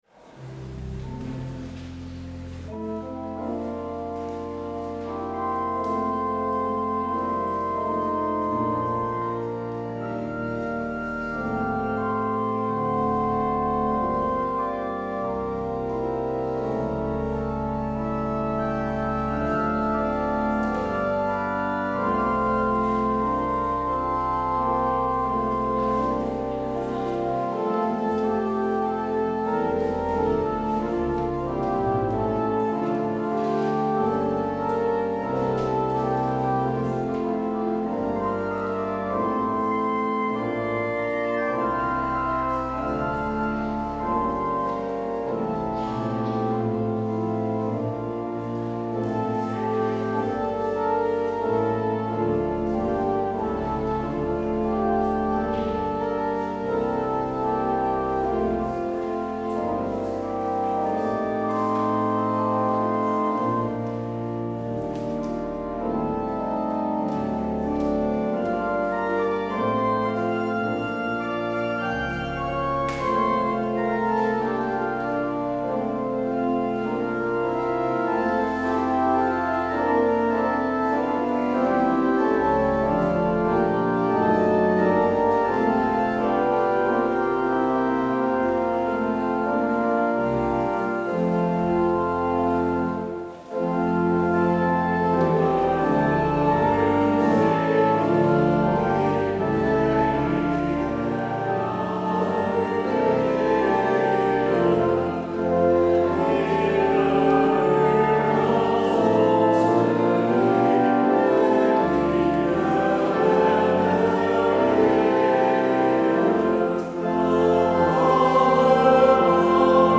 Als openingslied kunt u luisteren naar Lied 538: 1, 3 en 4.